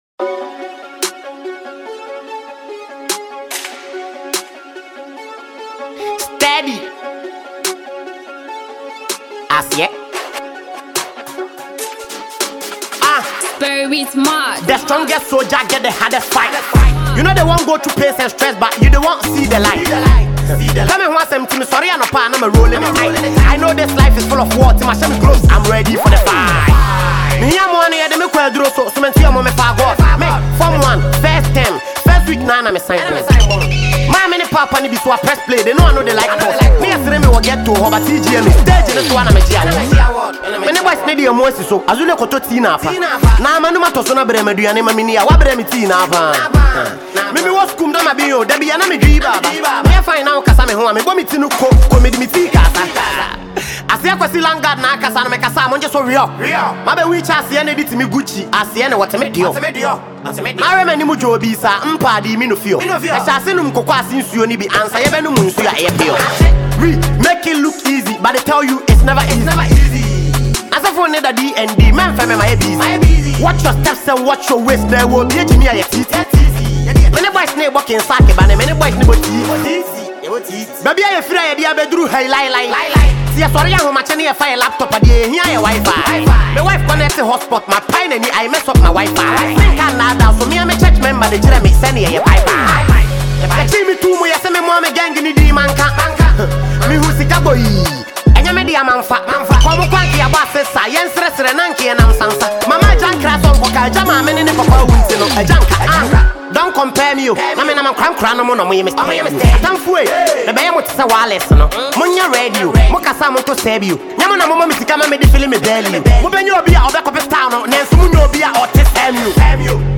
a Ghanaian rapper
rap song